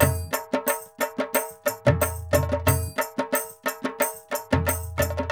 2021 Total Gabra Dholki Loops